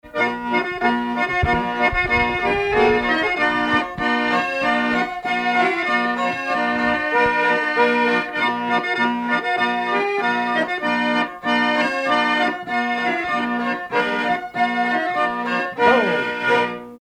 danse : quadrille
circonstance : bal, dancerie
Pièce musicale inédite